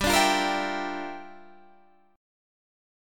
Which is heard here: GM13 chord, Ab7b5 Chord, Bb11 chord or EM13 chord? GM13 chord